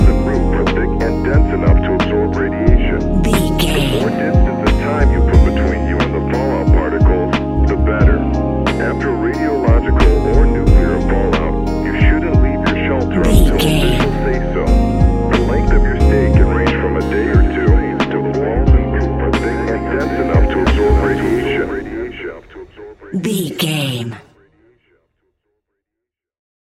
Ionian/Major
G♯
chilled
laid back
Lounge
sparse
new age
chilled electronica
ambient
atmospheric